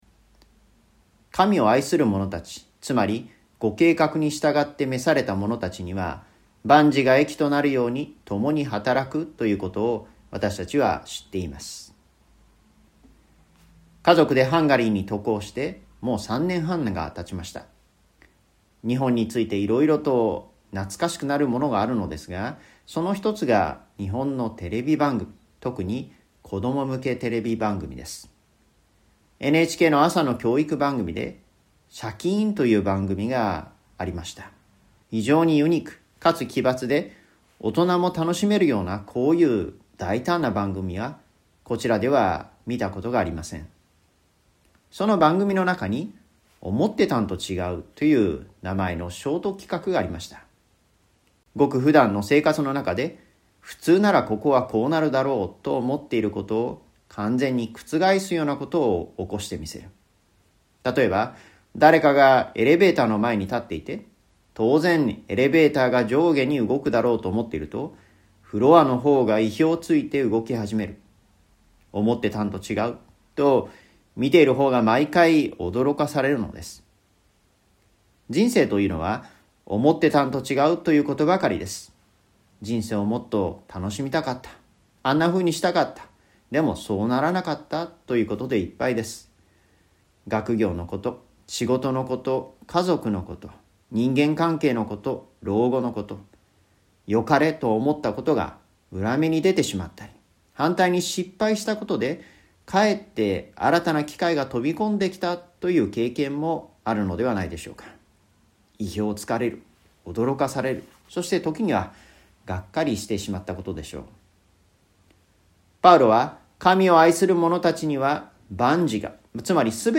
聖書はローマの信徒への手紙より ラジオ番組「キリストへの時間」